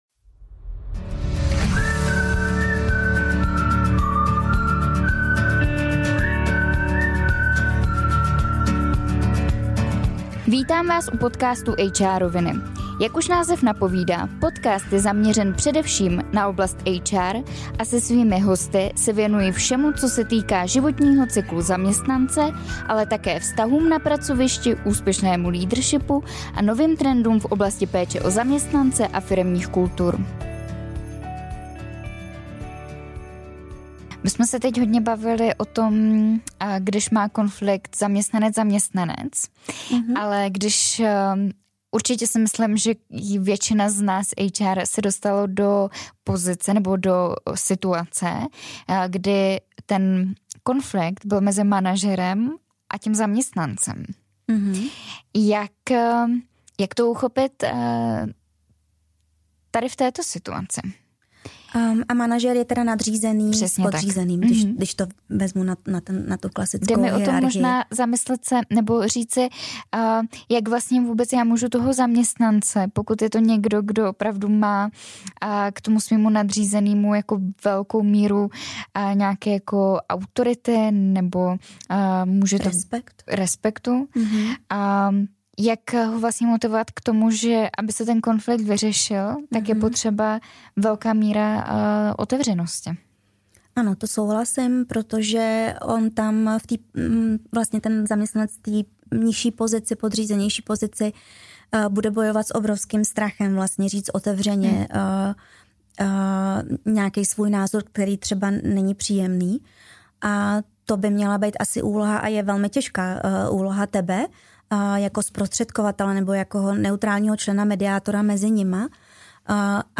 V pokračování rozhovoru s mediátorkou a komunikační koučkou